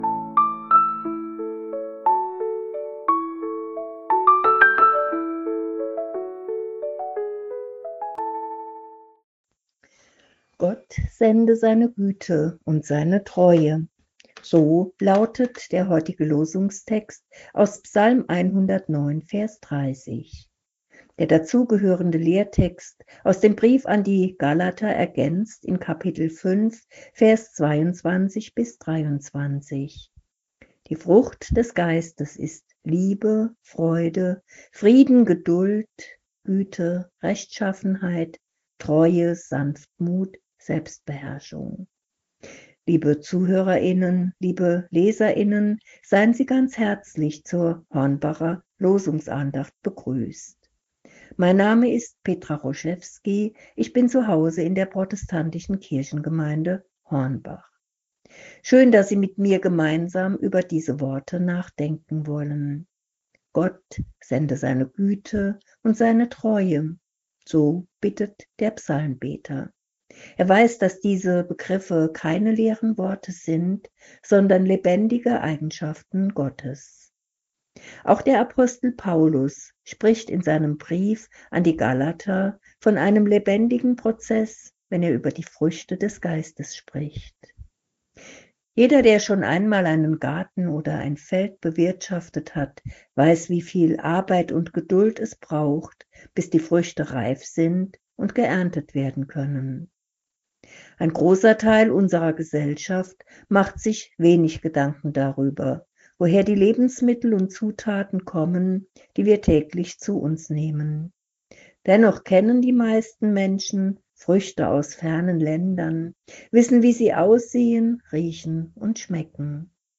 Losungsandacht für Montag, 08.09.2025